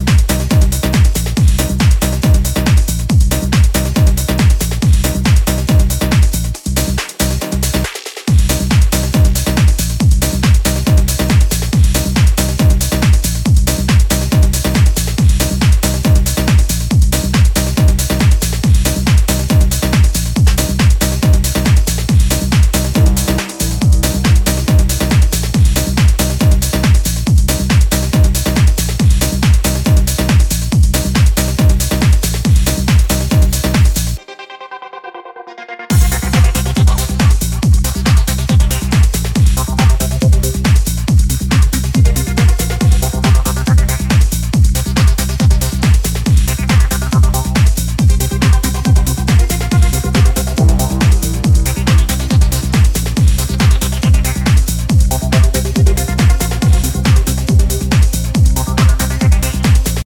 90’s テクノ的な煽るスタブとサイケな曲がりシンセの合わせ技でテンション高い